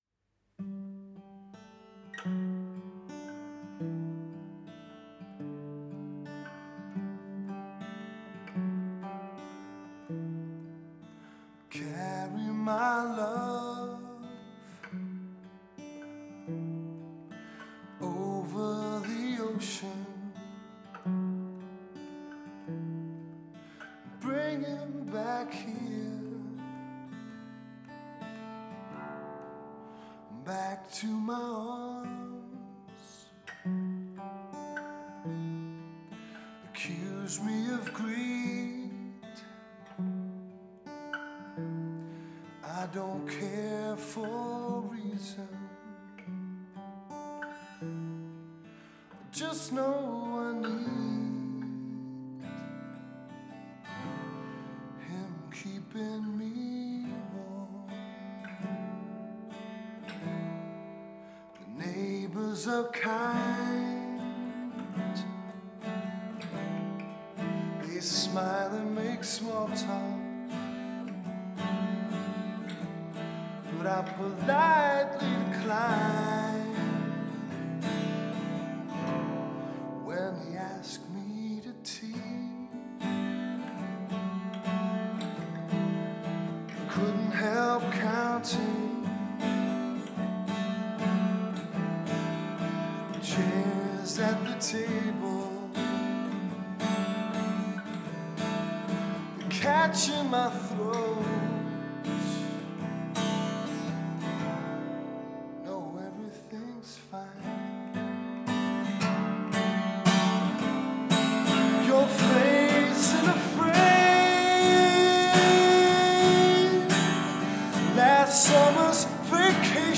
It was a real treat to be playing my songs in that place while the winter night seethed with jealousy outside.